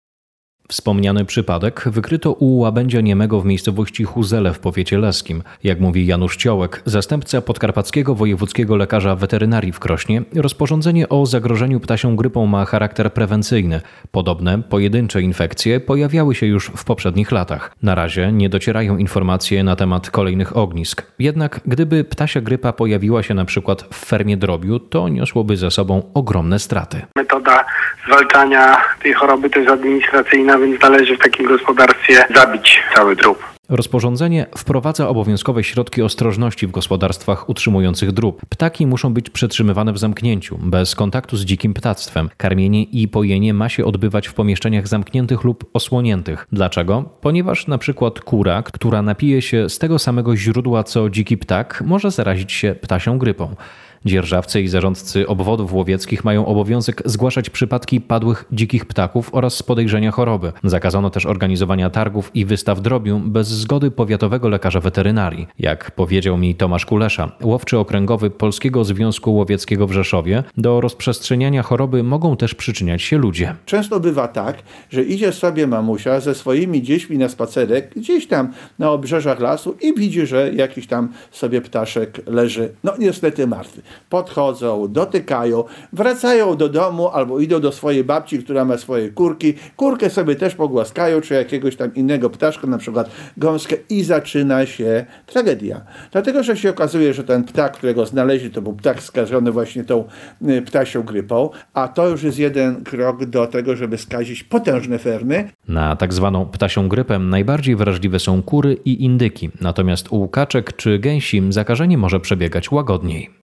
Nowe restrykcje dla hodowców • Relacje reporterskie • Polskie Radio Rzeszów